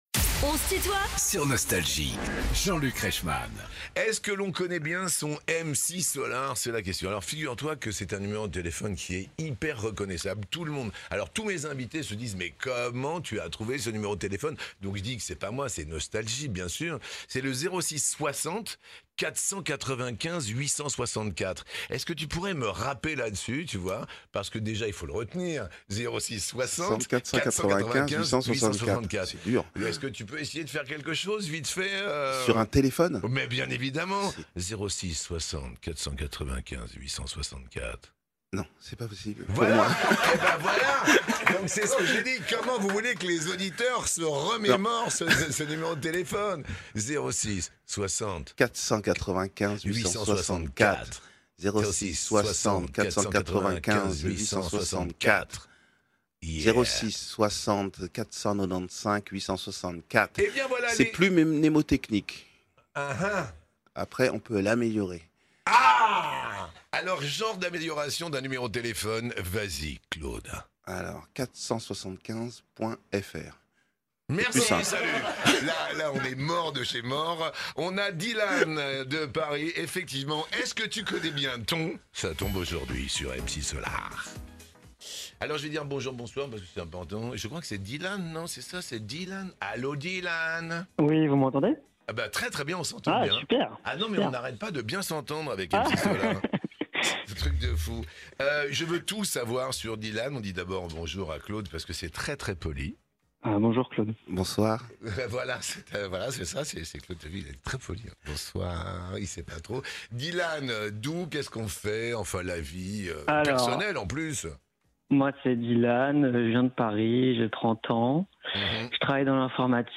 MC Solaar est l'invité de "On se tutoie ?..." avec Jean-Luc Reichmann